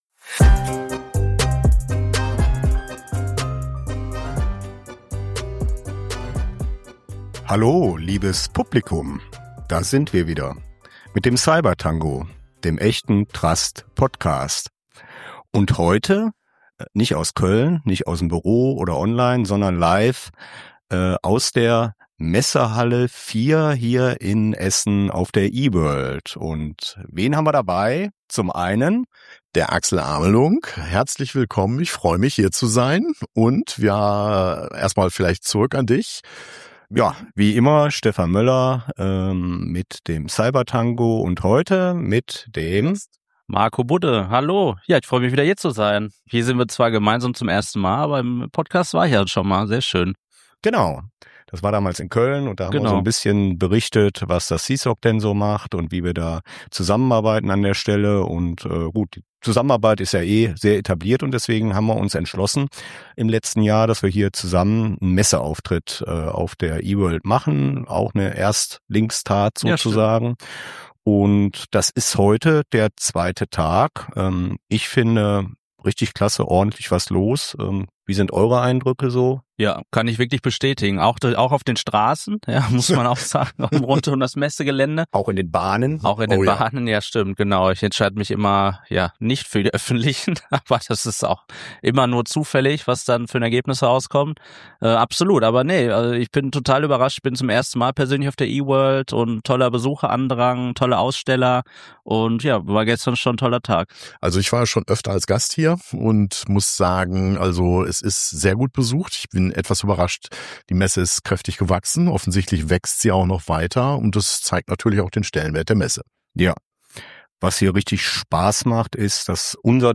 Heute gibt’s Podcast Atmosphäre direkt von der E World.